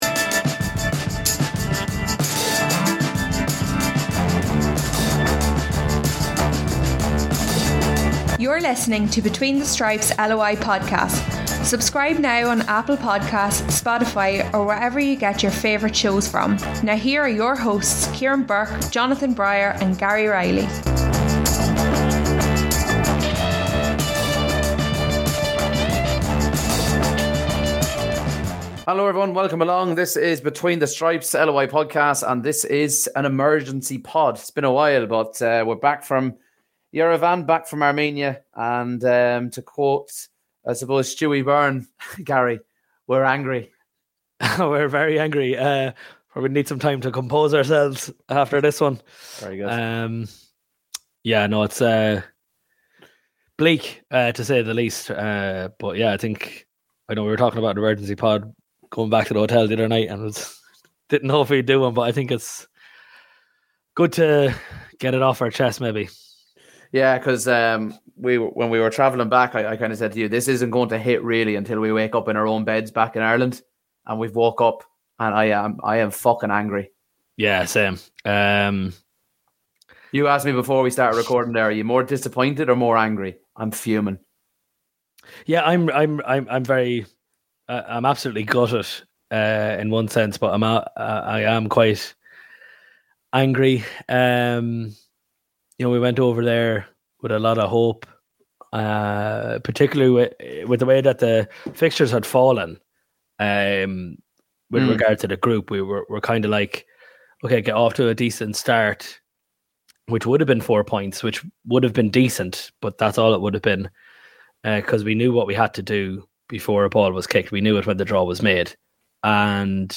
The lads are still fuming after Ireland’s disastrous night in Yerevan. Emotions are running high, and it’s gotten so bad that they’ve had to call an emergency pod. From the performance to the tactics (or lack of them), nothing is off-limits in this raw reaction.